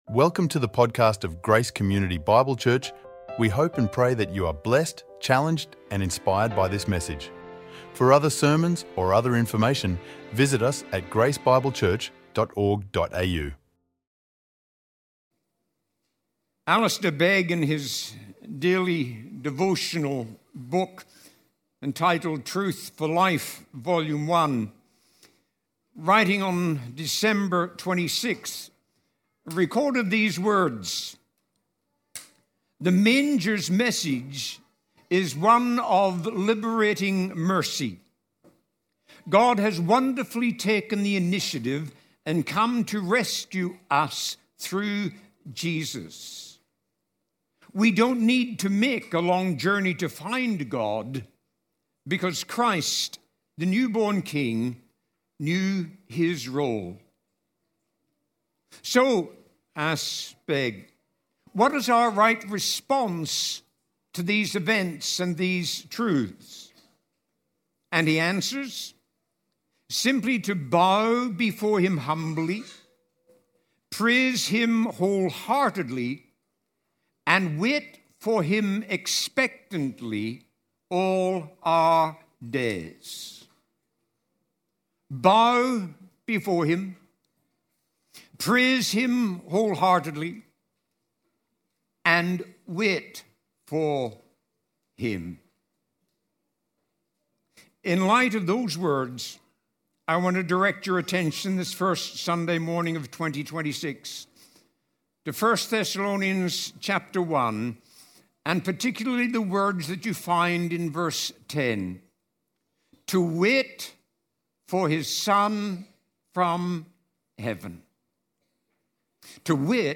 Guest Preacher
recorded live at Grace Community Bible Church